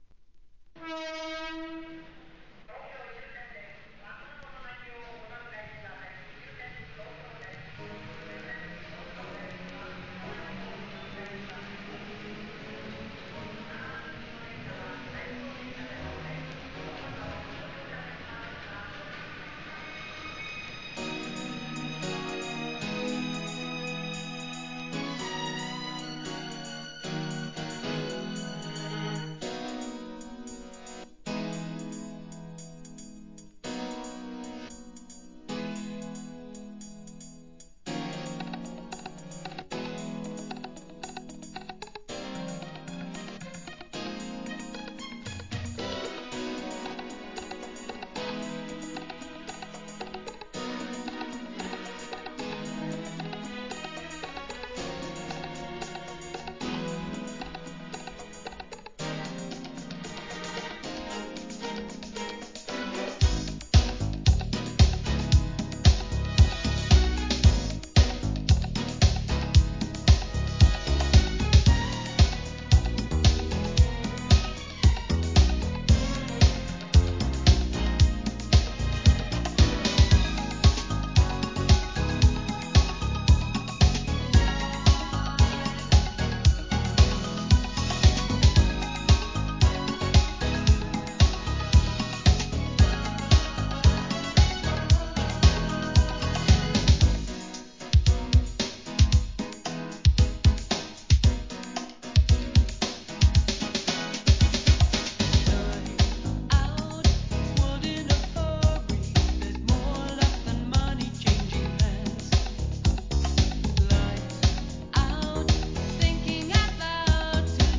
跳ね系のアップ・テンポ!